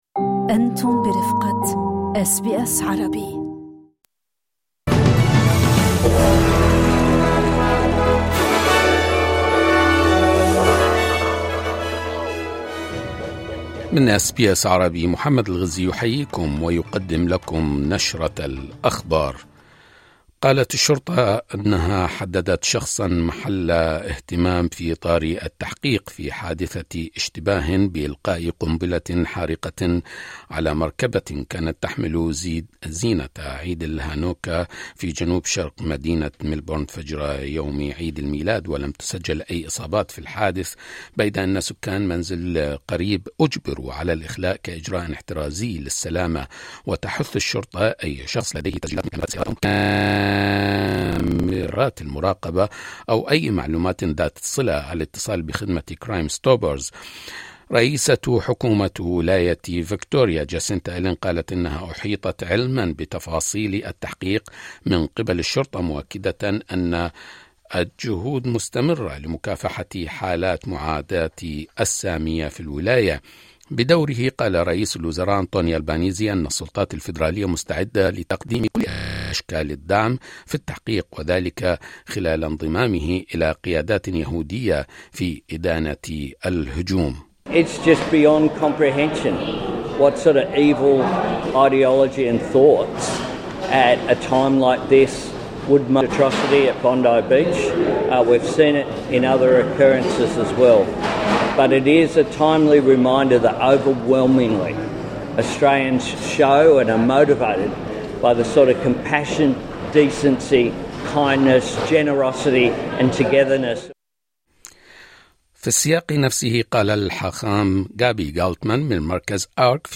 نشرة أخبار الظهيرة 26/12/2025